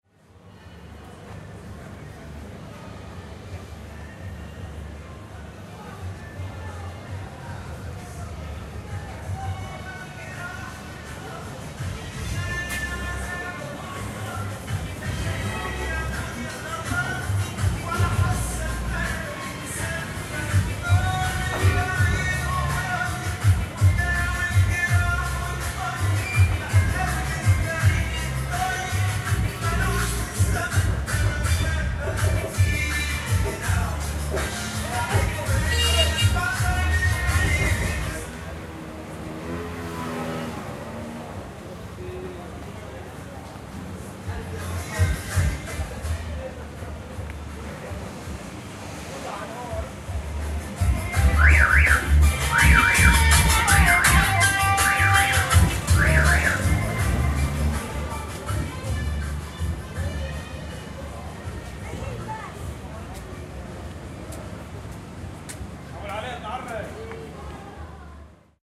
syriast-ambient_music-passing.ogg